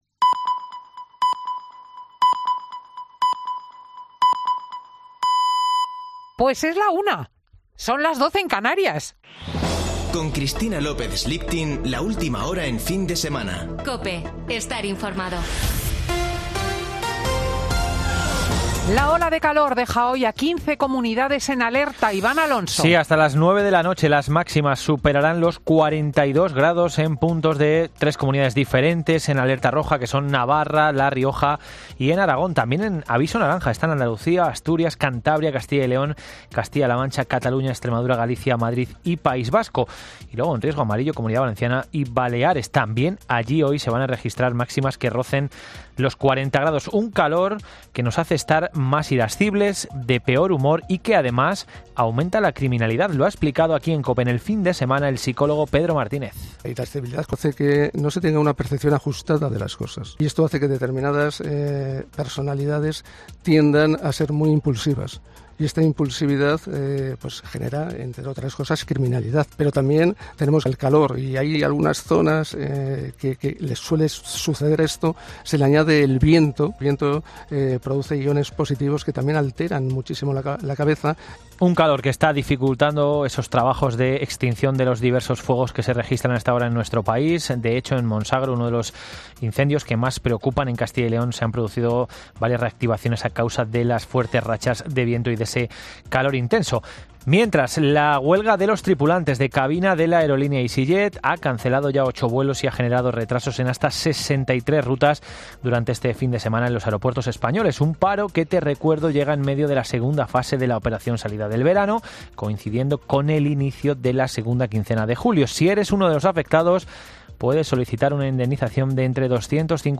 Boletín de noticias de COPE del 17 de julio de 2022 a las 13:00 horas